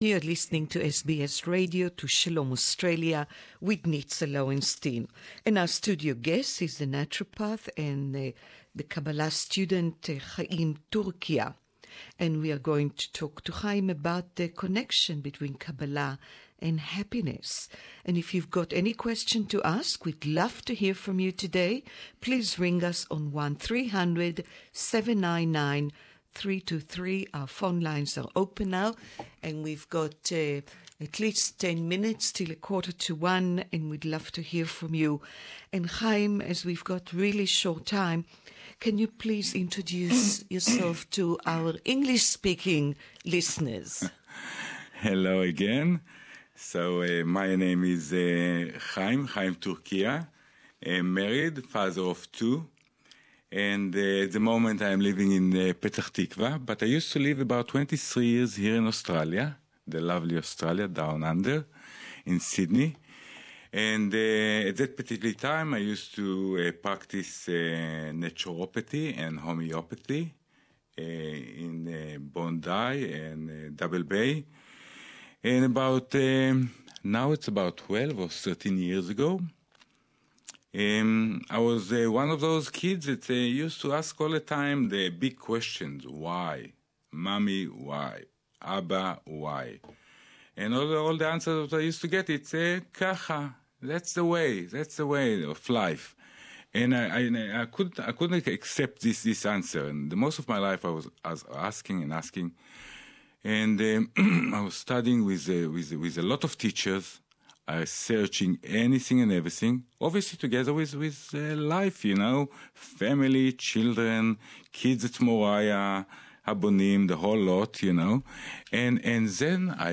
English interview